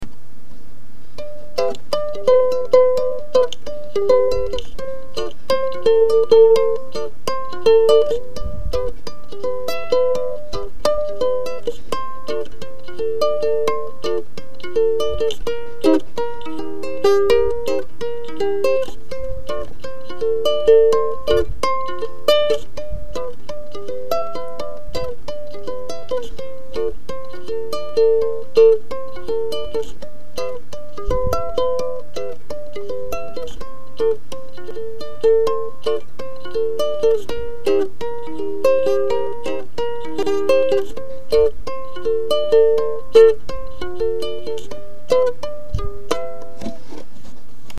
revenons au chose un peu plus acceptable. mon premier ukulélé.
tout érable avec table en épicéa. toujours pas mal de défaut de finition et surtout une table un poil trop épaisse qui bride bien le son. par contre, j’étais assez content de la rosace en forme de soleil couchant.